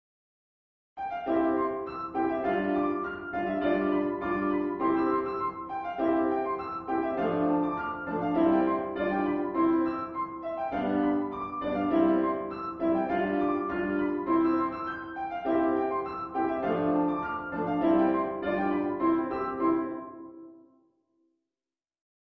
リピートは基本的に省略していますが、D.C.を含むものは途中のリピートも全て再現しています